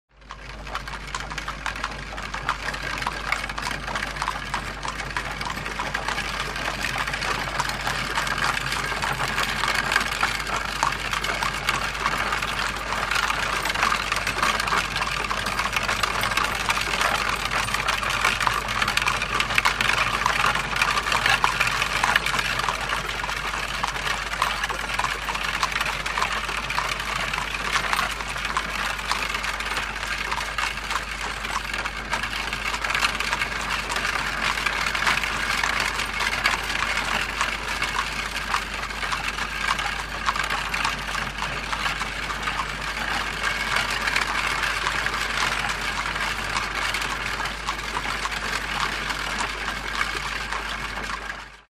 На этой странице собраны натуральные звуки телег и повозок: скрип деревянных колес, цоканье копыт лошадей, шум движения по грунтовой дороге или брусчатке.
Шум кареты с лошадьми